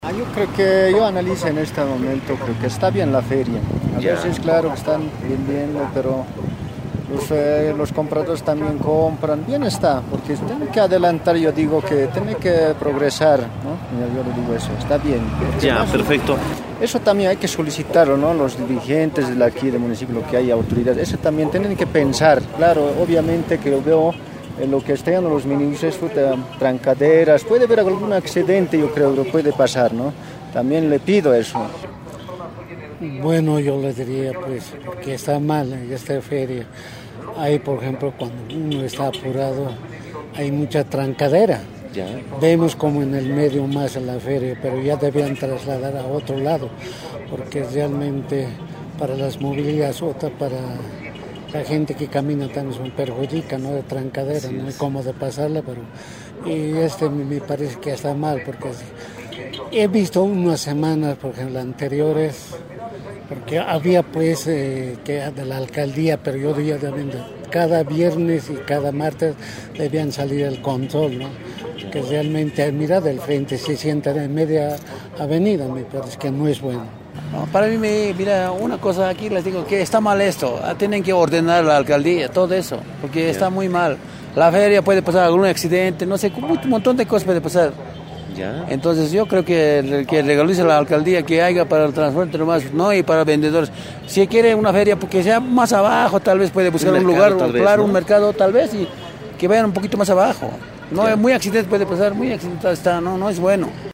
Radio integración hizo el recorrido de este sector hablando con vendedores, choferes y peatones donde la ausencia de ordenamiento y el control por parte de la institución edil no se ve a ningún lado.